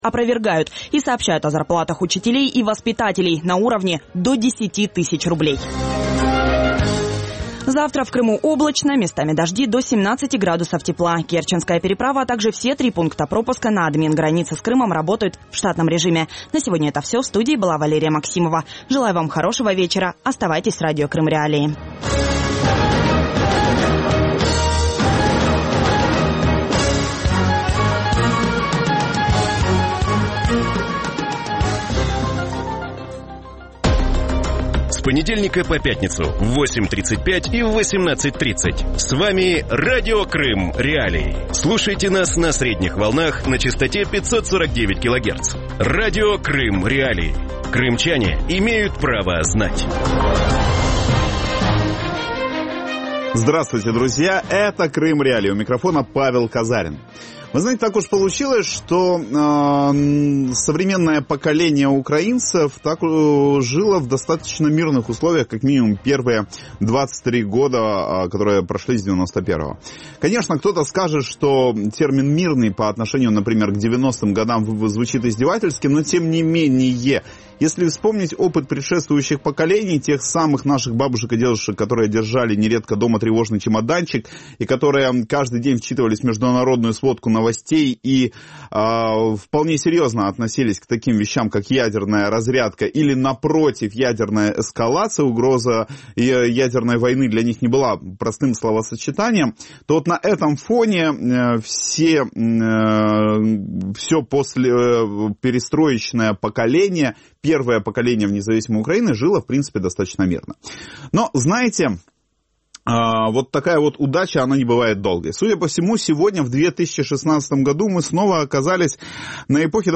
У вечірньому ефірі Радіо Крим.Реалії обговорюють погіршення російсько-американських відносин і поправки до російського бюджету, які передбачають зниження витрат у соціальній сфері та збільшення оборонних витрат. Про що свідчить припинення співпраці Росії і США щодо врегулювання сирійського питання, призупинення договору про утилізацію плутонію і чи готується Росія прийняти бюджет військового часу?